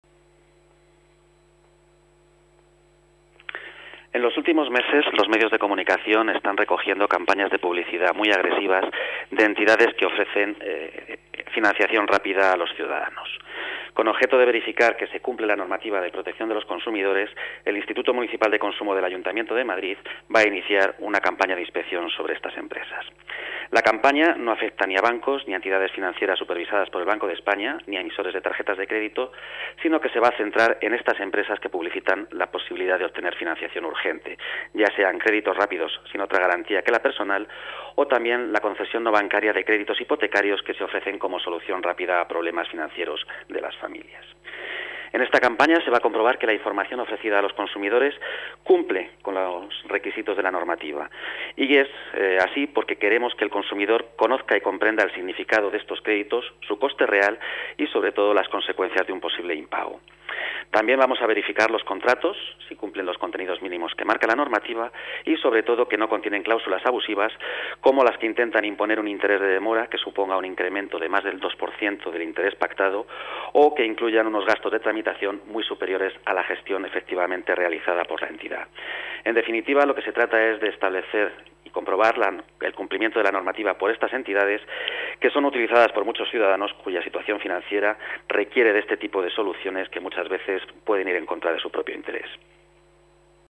Nueva ventana:Declaraciones del director general de Consumo, Ángel Sánchez